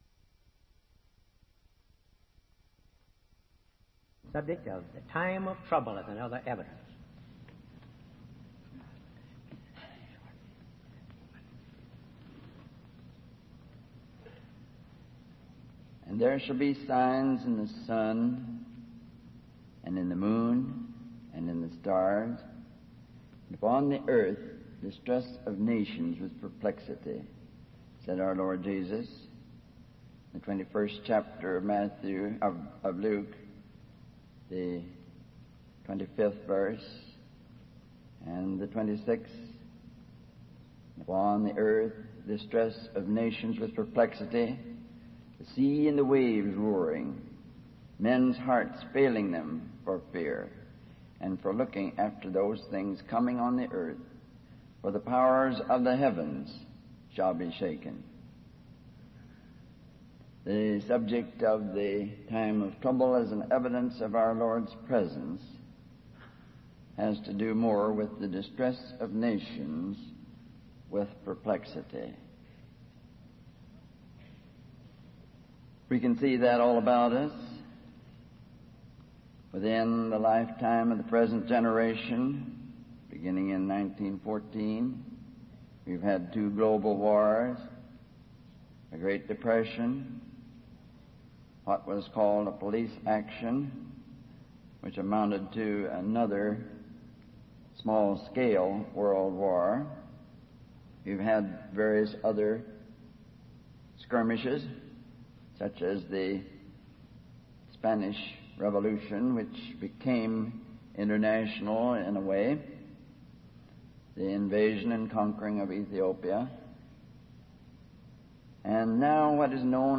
From Type: "Symposium"